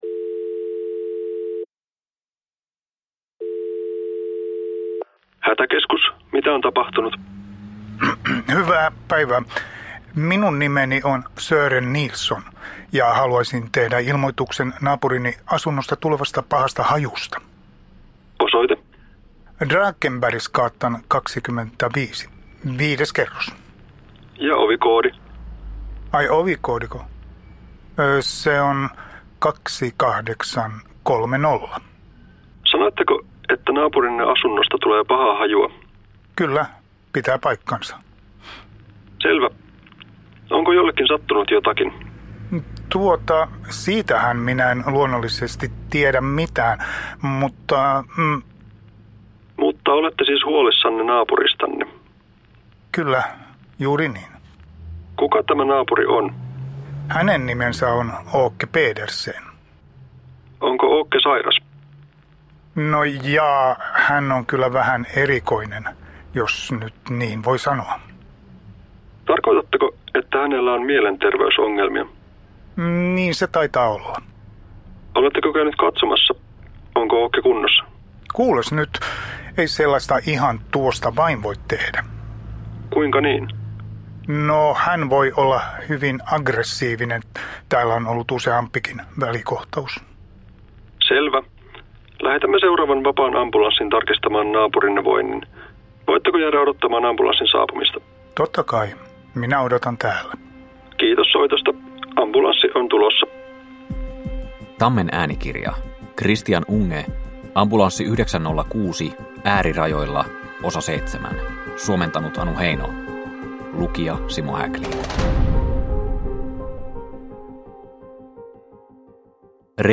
Ambulanssi 906 Osa 7 – Ljudbok – Laddas ner